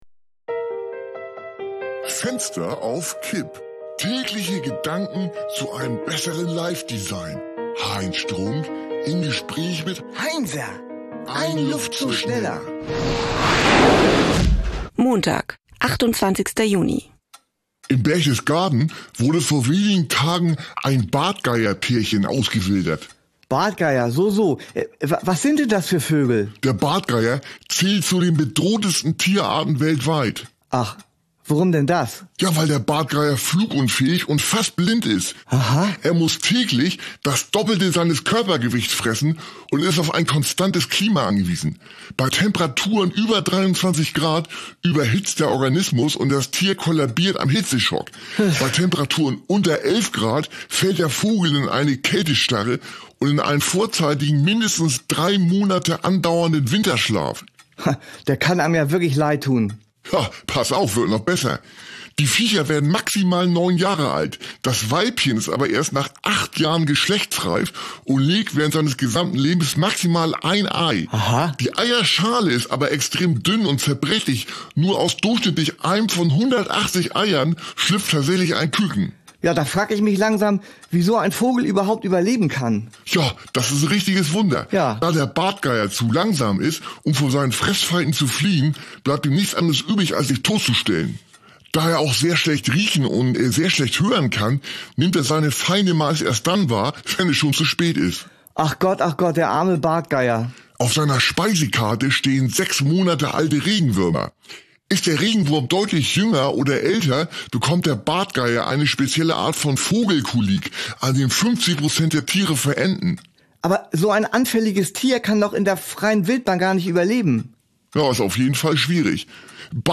eine Audio-Sitcom von Studio Bummens